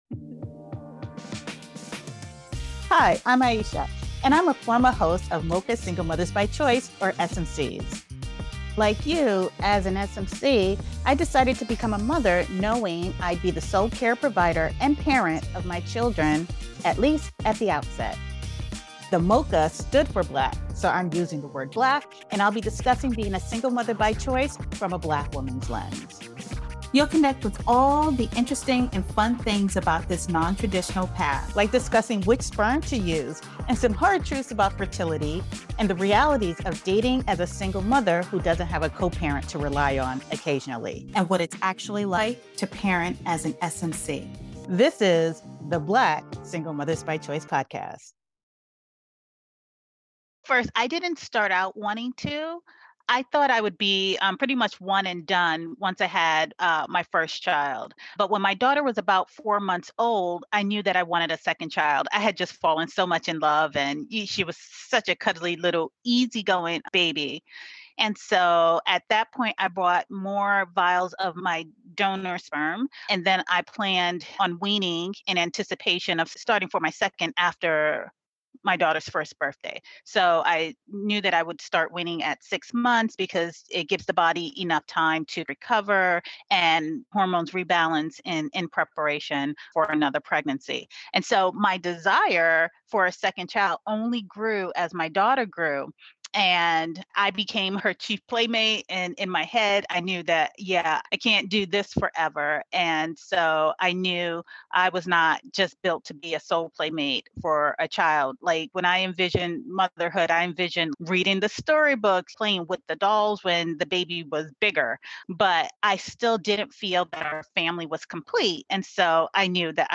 Tune in for an honest and heartfelt conversation, and don't forget to subscribe to the Black Single Mothers by Choice and Start to Finish Motherhood YouTube channels and IG pages.